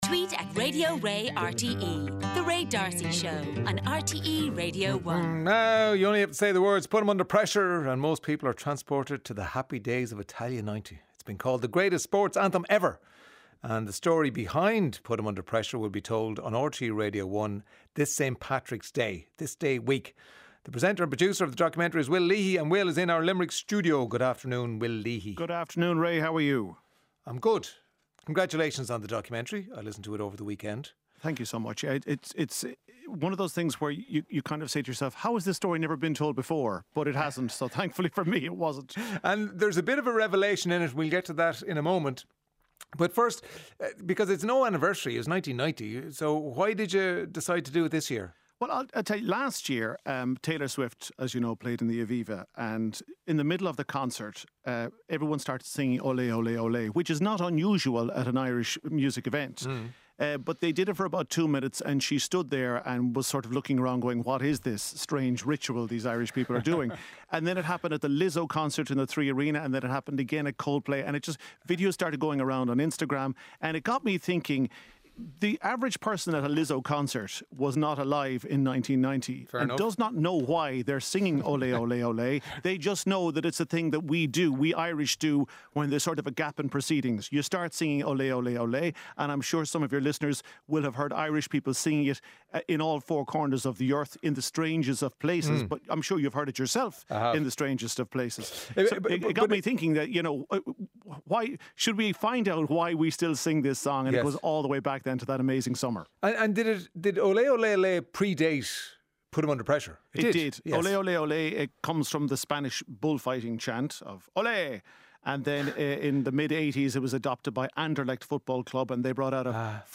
Highlights from the daily radio show with Ray D'Arcy. Featuring listeners' stories and interviews with authors, musicians, comedians and celebrities.